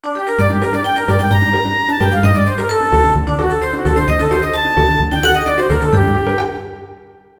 Tonalidad de Sol menor. Ejemplo.
tristeza
dramatismo
melodía
severo
sintetizador